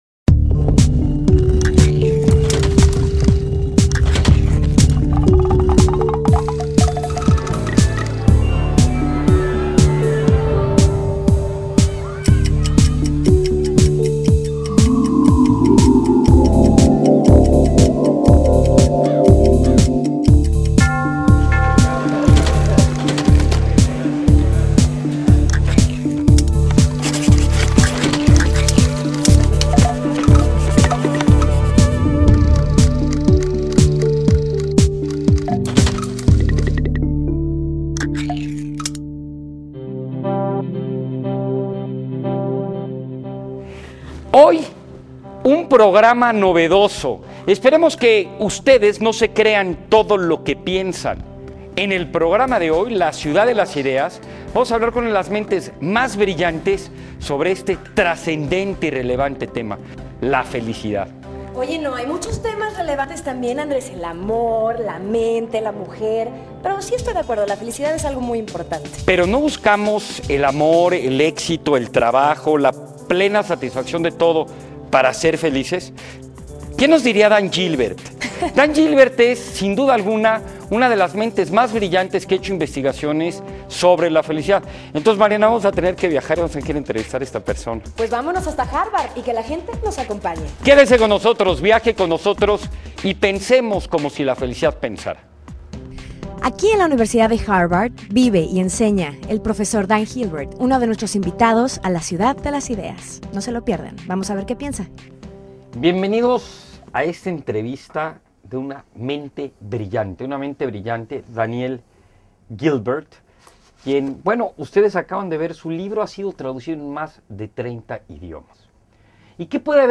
Entrevista sobre la felicidad
Entrevista-Daniel-Gilbert-La-Ciudad-de-las-Ideas.mp3